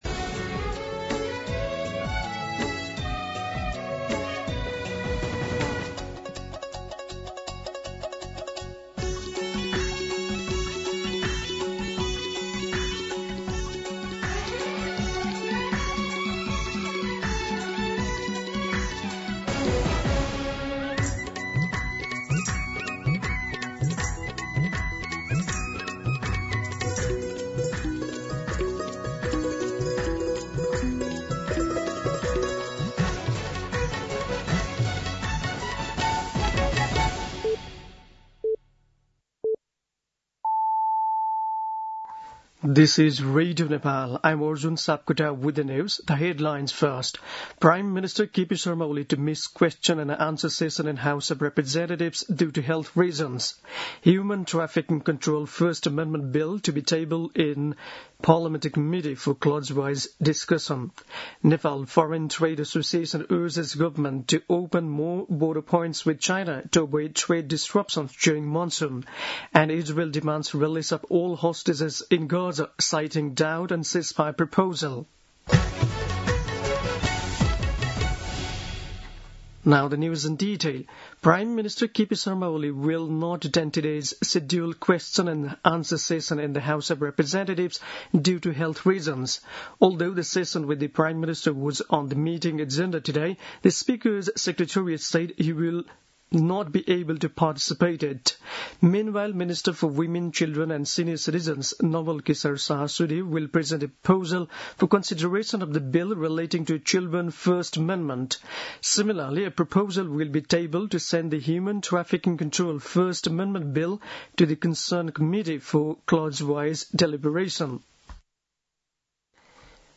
दिउँसो २ बजेको अङ्ग्रेजी समाचार : ४ भदौ , २०८२
2pm-News-05-4.mp3